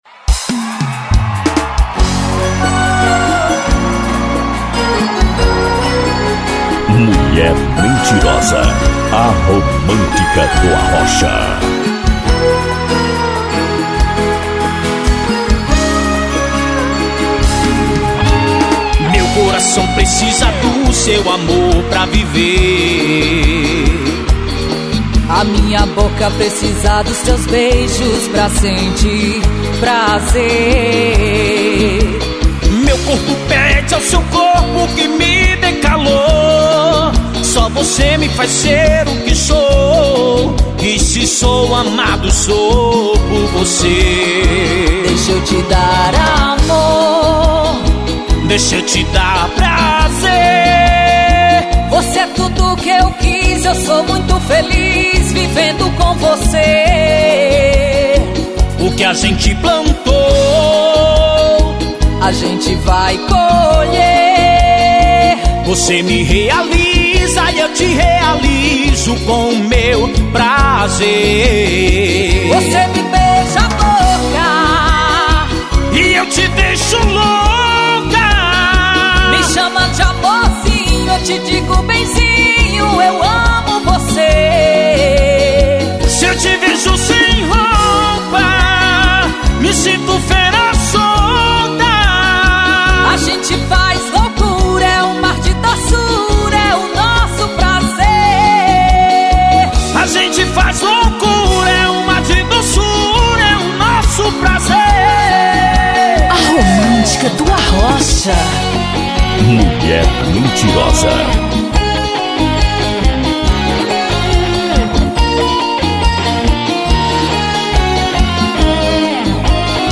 na pegada do arrocha